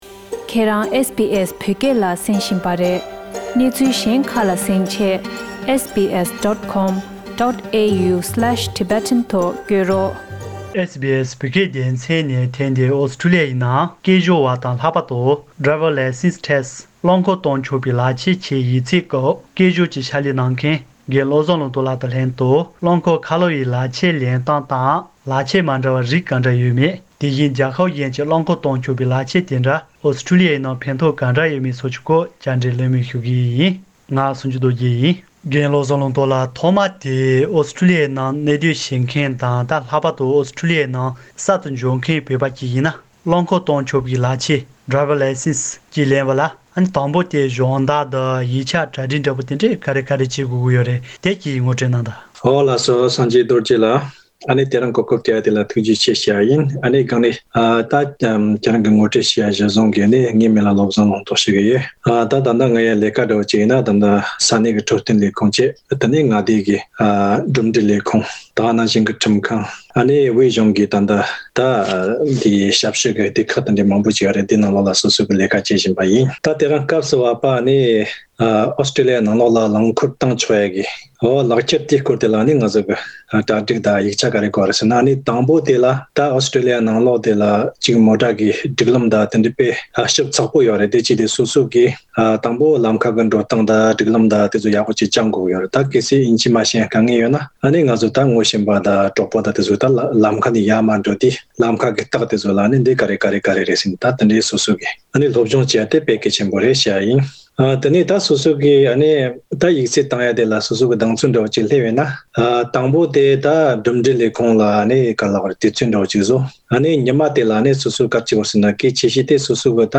བཅར་འདྲི་གླེང་མོལ་ཞུས་པ།